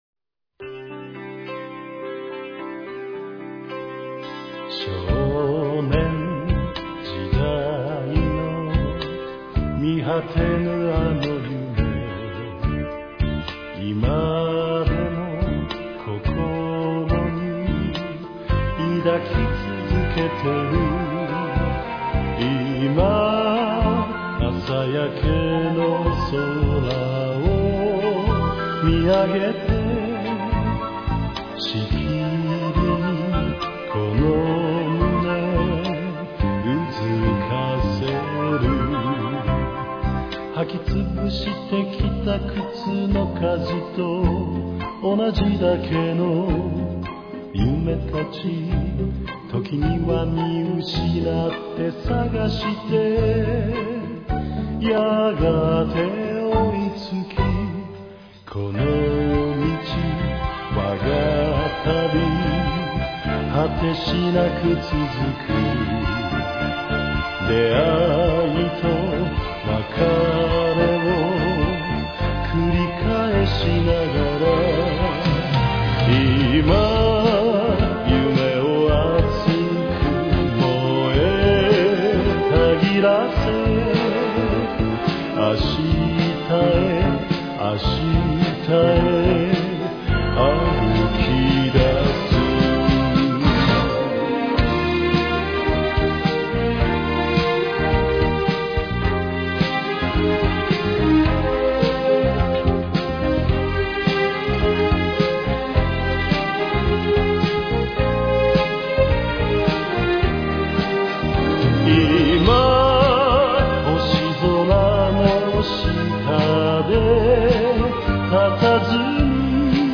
Original, version completa en japones.